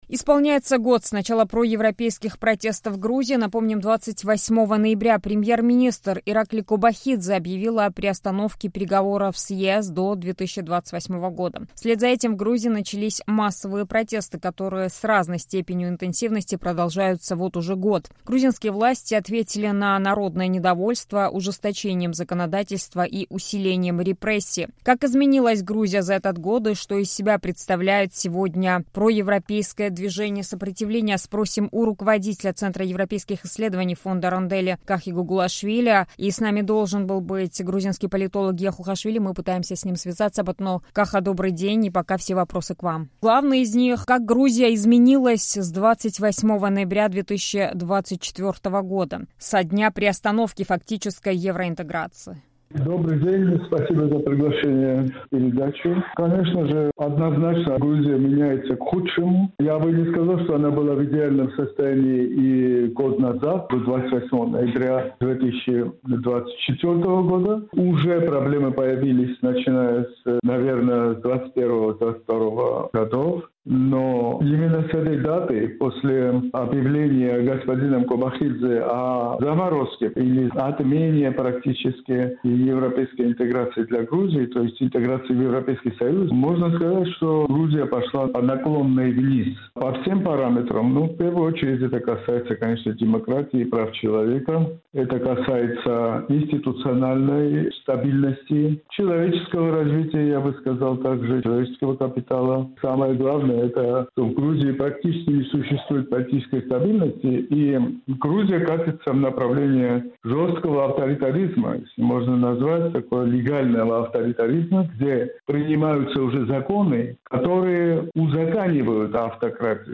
Рубрика Некруглый стол, разговор с экспертами на самые актуальные темы